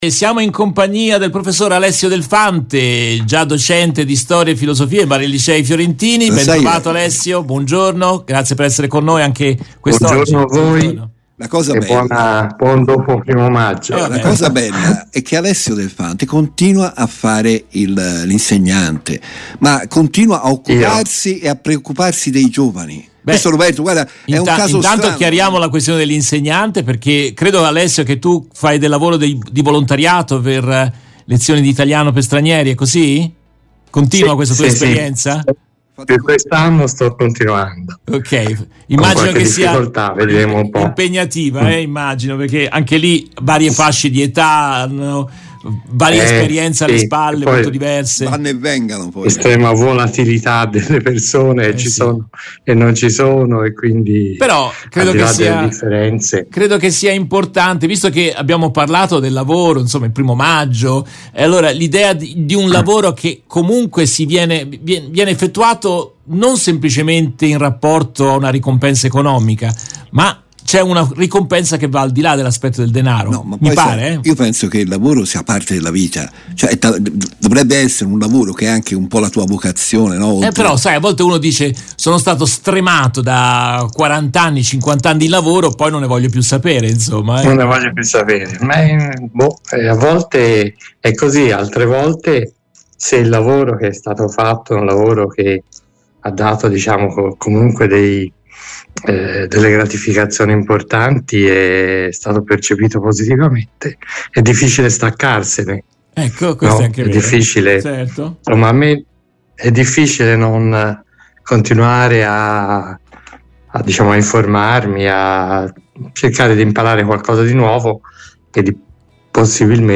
Nel corso della diretta RVS